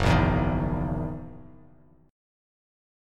F#13 chord